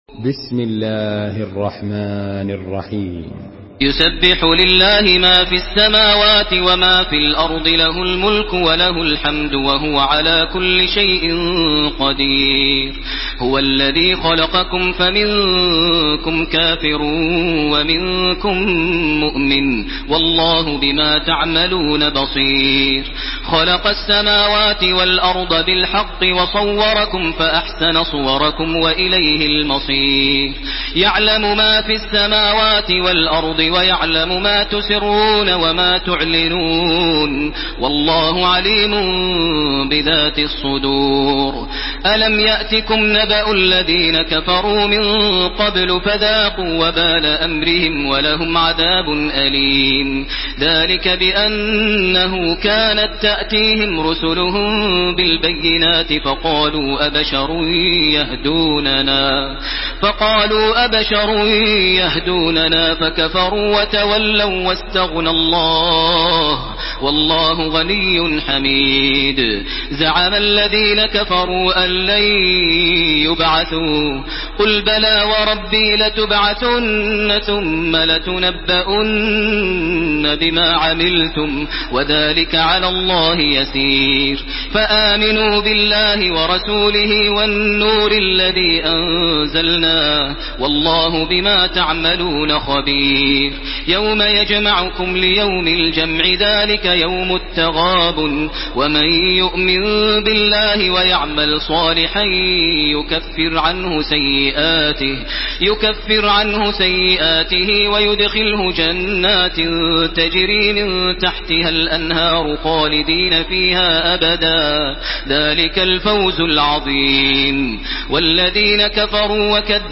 Surah Tegabun MP3 by Makkah Taraweeh 1431 in Hafs An Asim narration.
Murattal Hafs An Asim